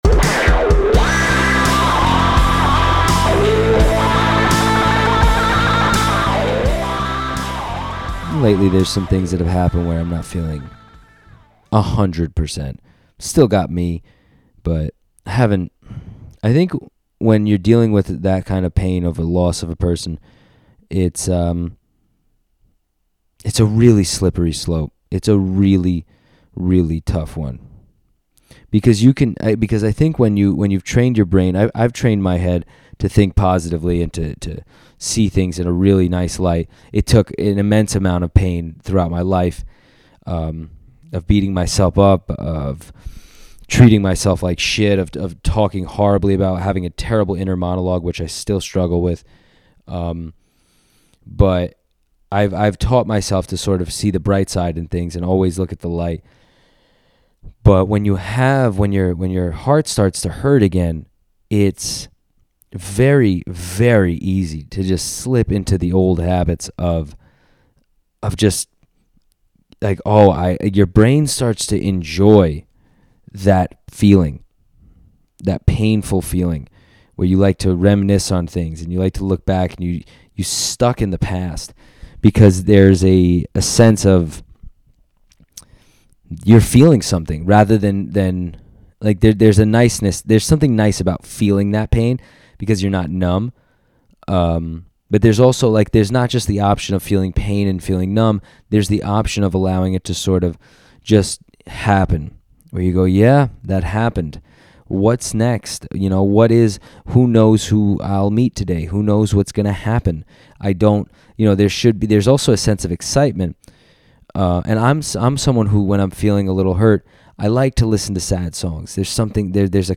I wasn't feeling all there this week, so I did a solo one. I feel like this episode has two halves, two vibes really.